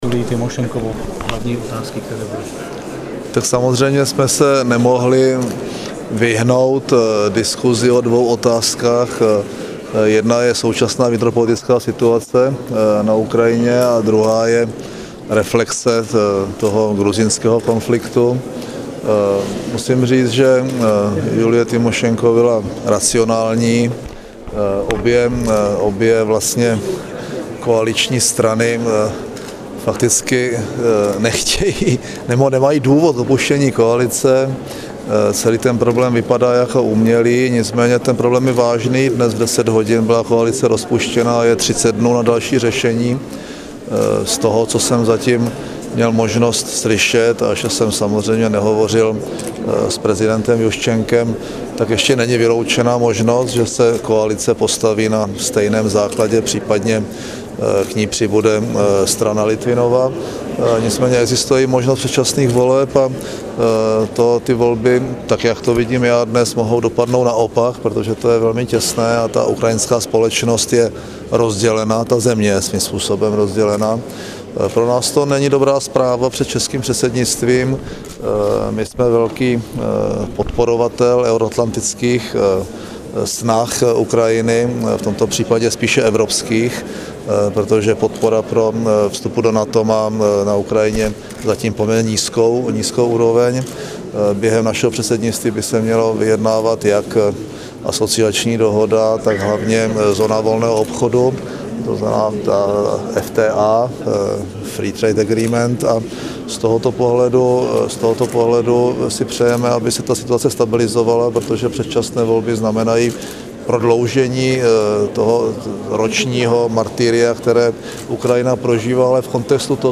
Audiozáznam rozhovoru s premiérem M. Topolánkem